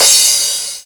073 - Crash-1.wav